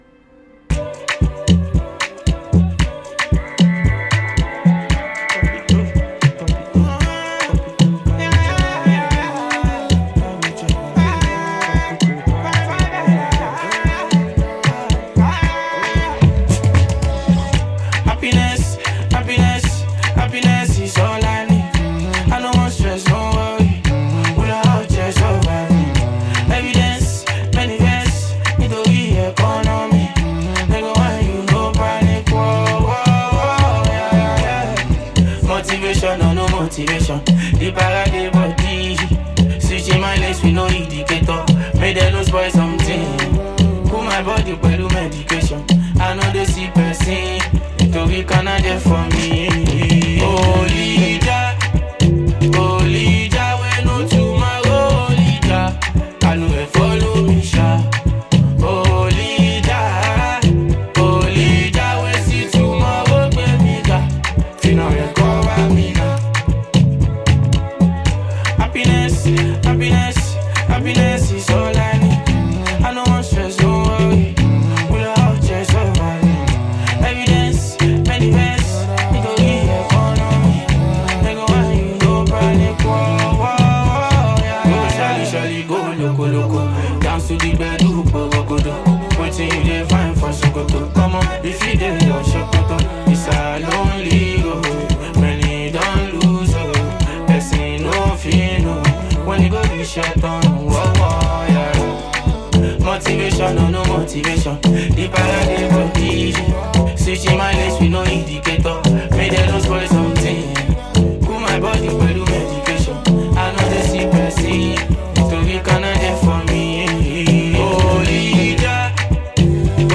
is a soulful and uplifting record